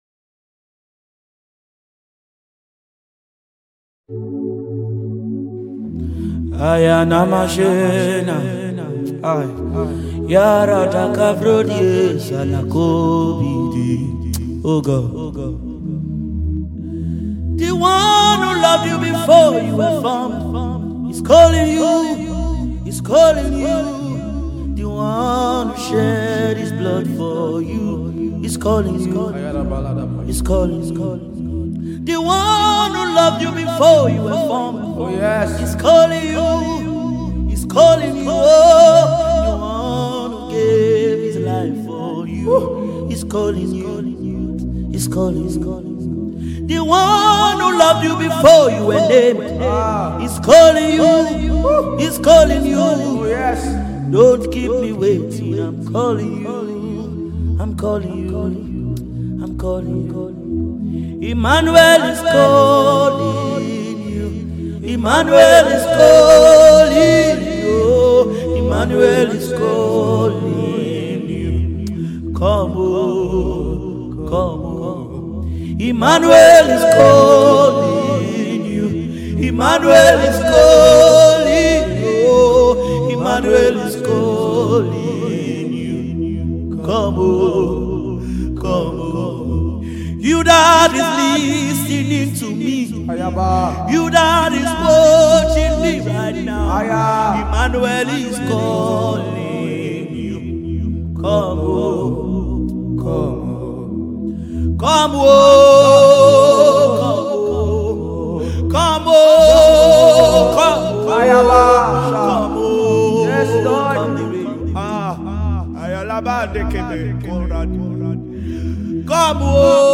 soul lifting single
a song of worship and adoration